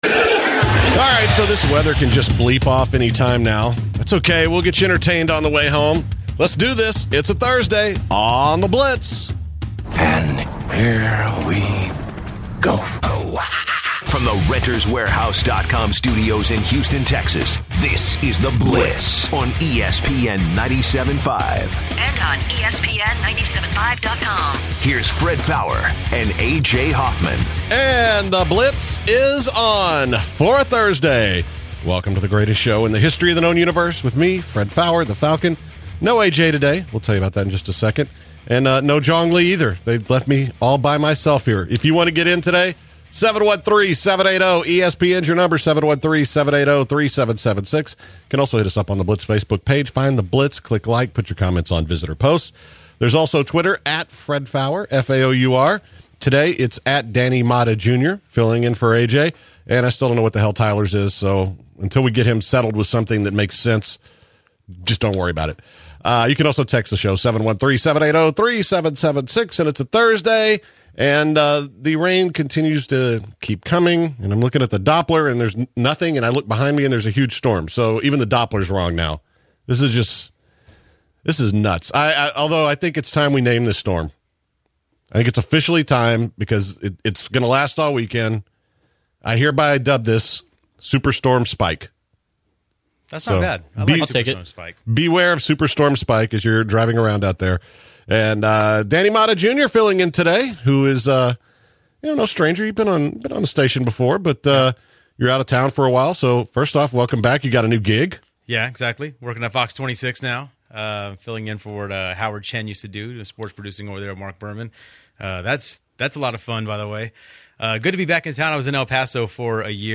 Will Lebron's legacy take a hit if they fall to the warriors. Also, the Blitzer’s call in to find out if trade rumors for Ryan Braun are true. The guys also discuss Game of Thrones, and did a racial joke go a little too far?